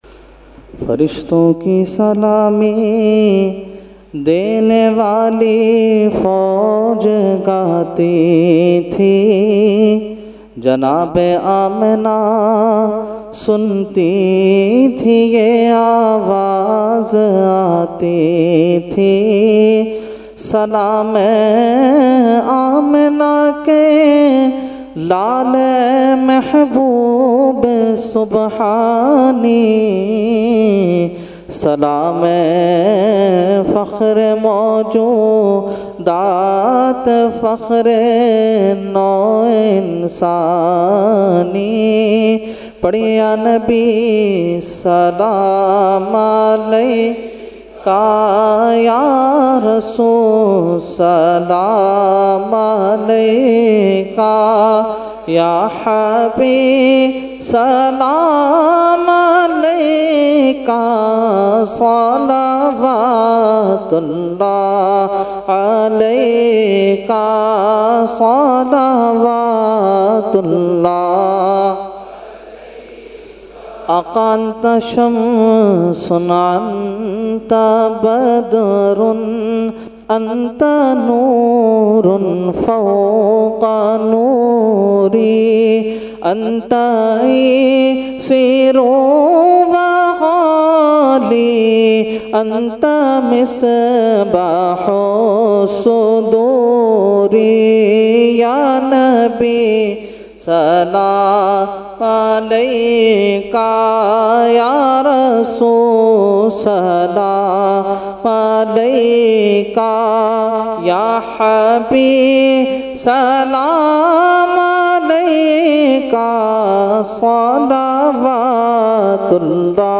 Jashne Subhe Baharan held 29 October 2020 at Dargah Alia Ashrafia Ashrafabad Firdous Colony Gulbahar Karachi.
Category : Salam | Language : ArabicEvent : Jashne Subah Baharan 2020